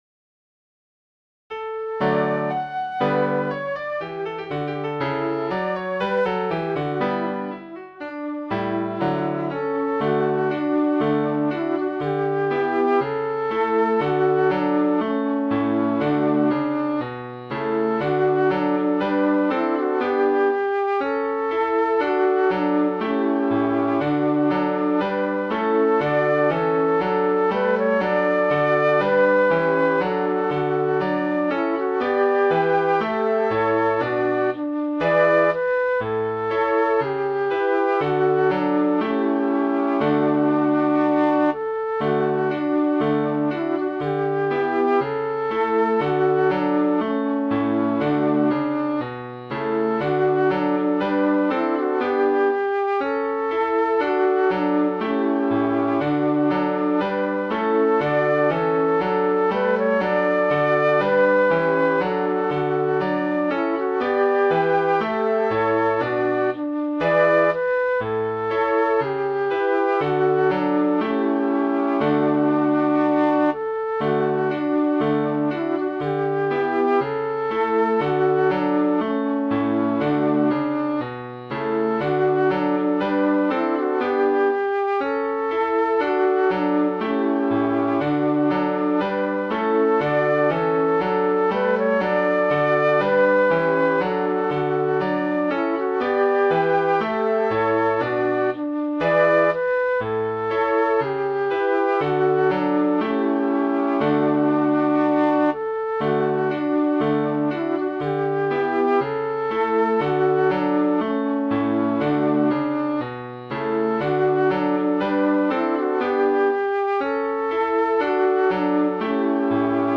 Midi File, Lyrics and Information to Good Morning, Pretty Maid